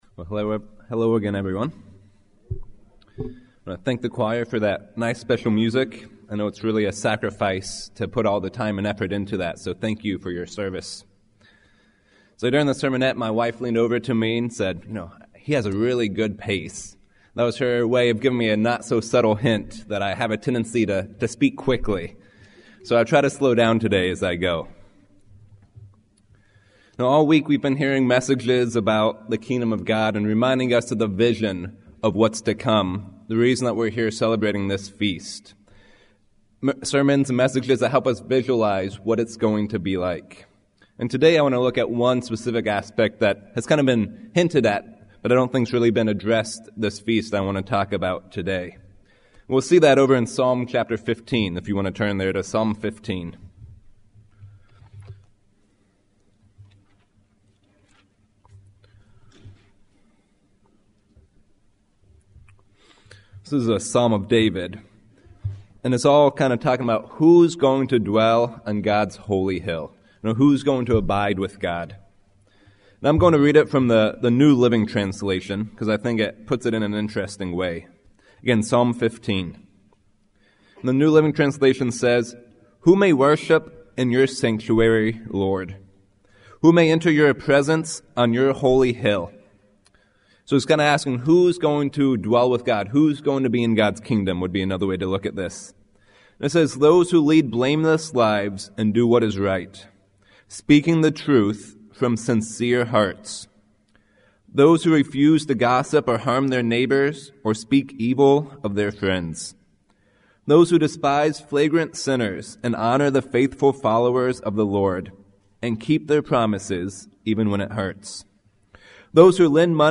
This sermon was given at the Jekyll Island, Georgia 2016 Feast site.